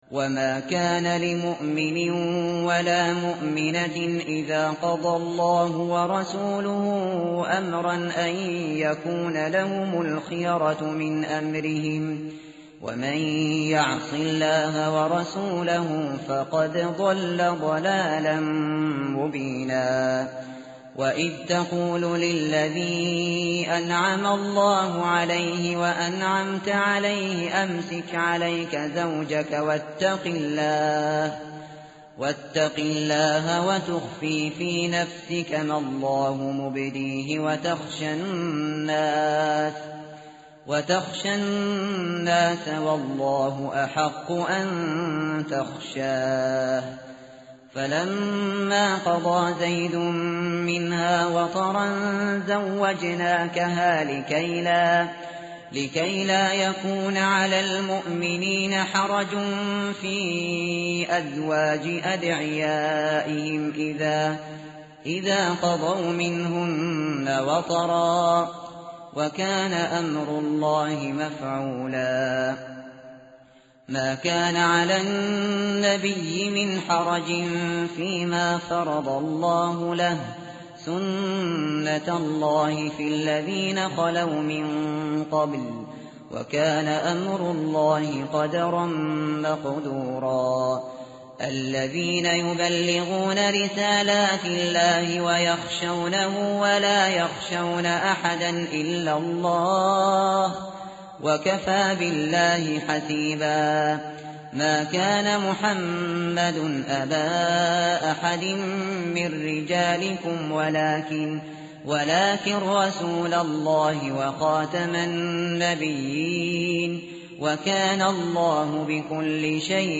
متن قرآن همراه باتلاوت قرآن و ترجمه
tartil_shateri_page_423.mp3